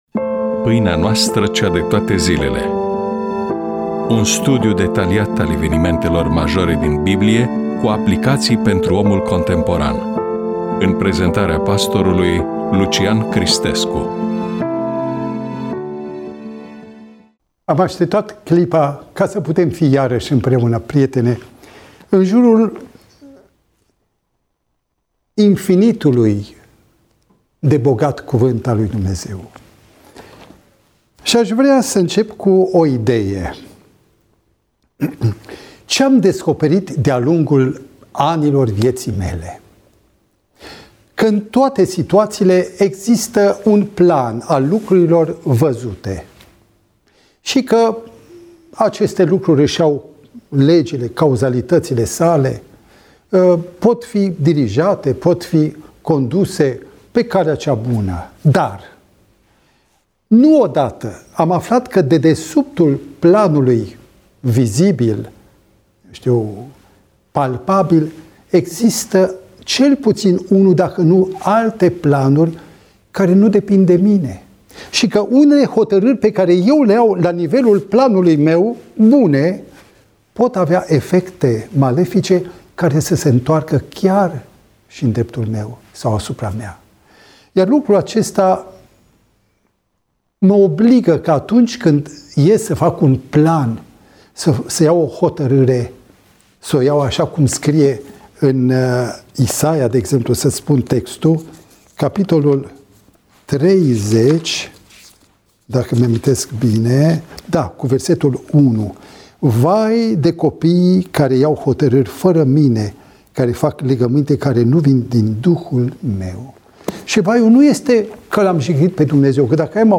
EMISIUNEA: Predică DATA INREGISTRARII: 24.04.2026 VIZUALIZARI: 12